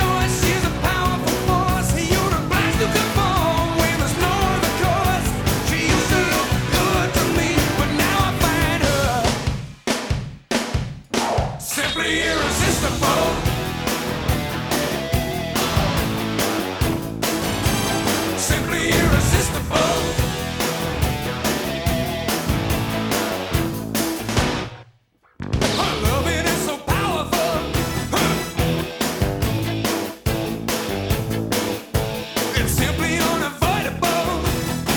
Жанр: Поп музыка / Рок / R&B / Соул